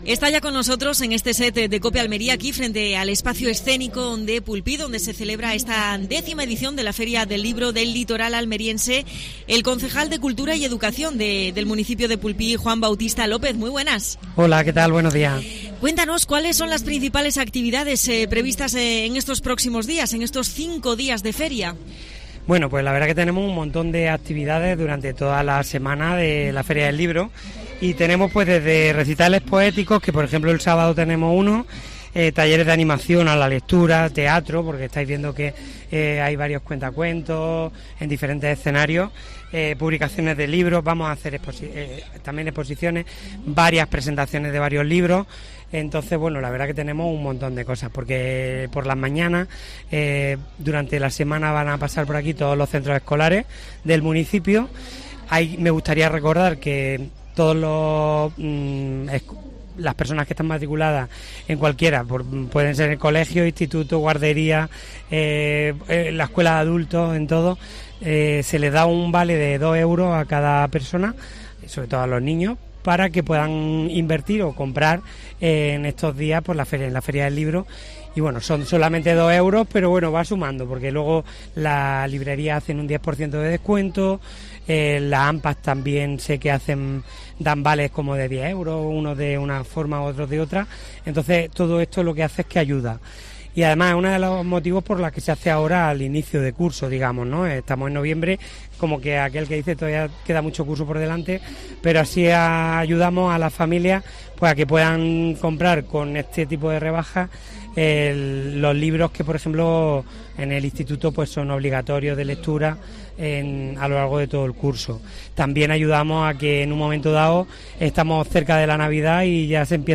AUDIO: Entrevista al concejal de Cultura de Pulpí con motivo de la Feria del Libro de la localidad almeriense.